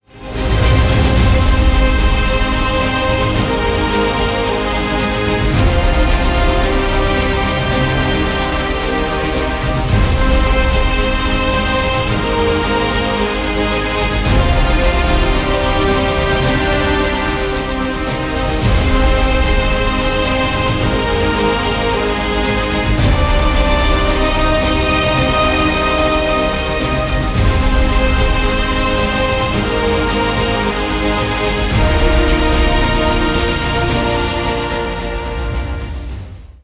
Мелодии на звонок
Нарезка на смс или будильник